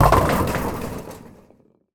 bowling_ball_pin_strike_02.wav